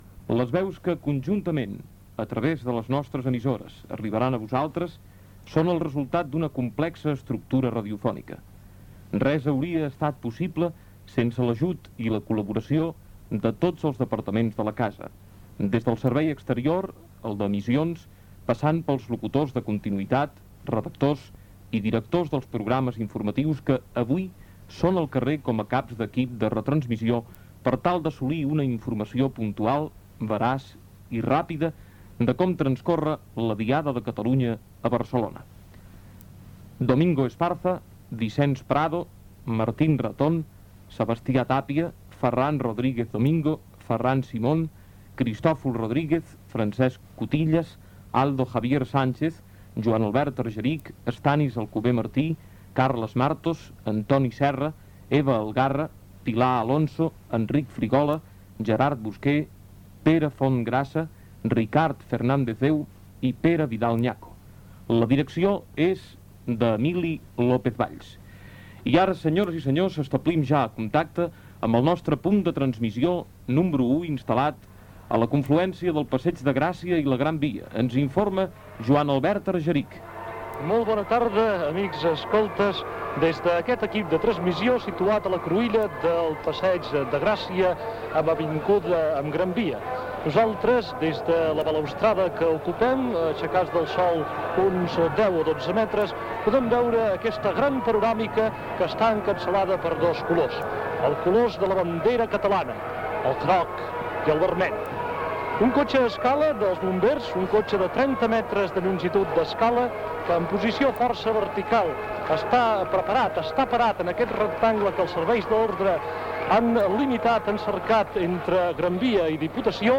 Transmissió de la Diada Nacional de Catalunya, equip tècnic i periodístic que la faran.Connexions amb el Passeig de Gràcia, monument de Rafael Casanova
Informatiu